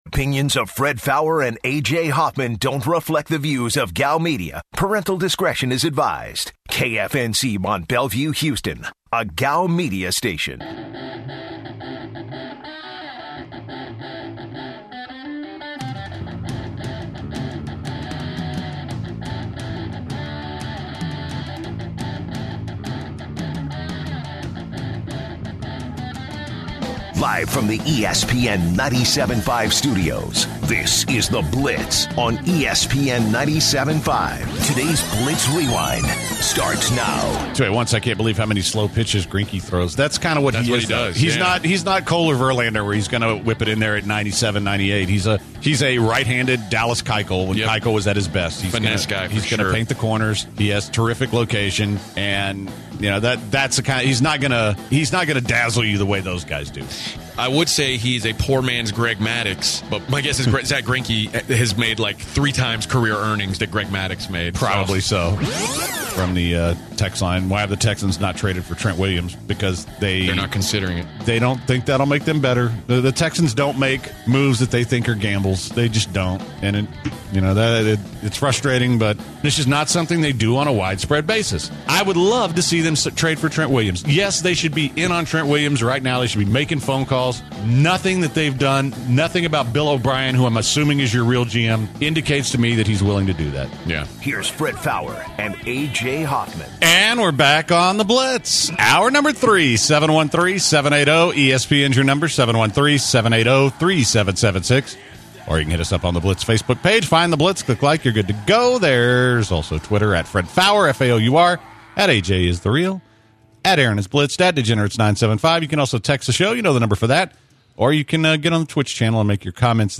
What is the best chain pizza restaurant? A caller is going to Vegas for the first time and needs advice.